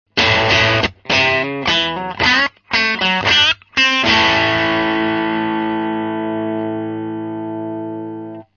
(NoEQ,NoEffectで掲載しています）
No.6 MP3 Hexaのパッチケーブルを一本使用。
プレゼンスが効いてない、そんな印象の音です。これは結構予想していたより良くないサウンドでした。